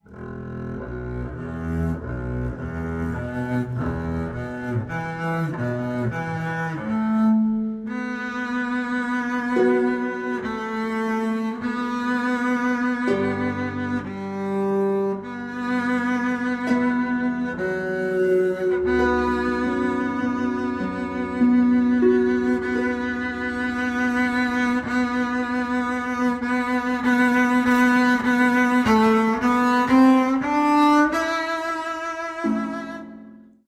1 Talk